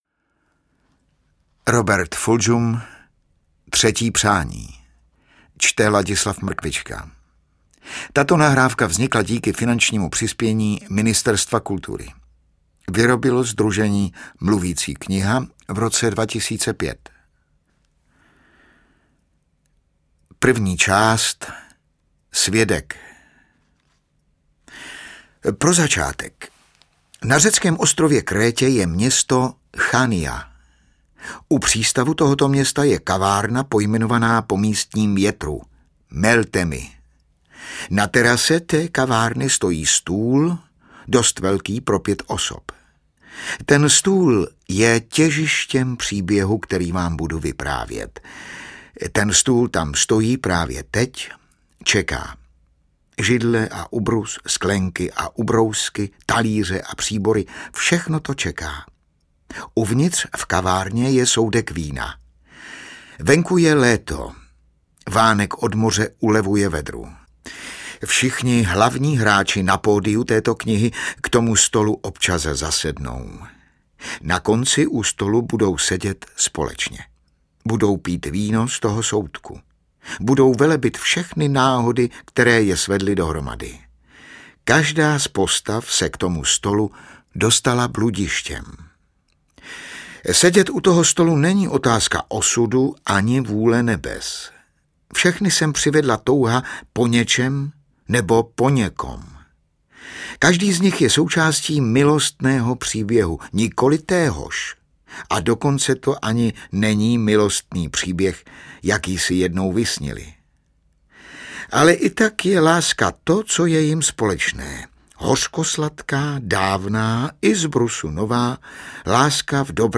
Mluvící kniha z.s.
Čte: Ladislav Mrkvička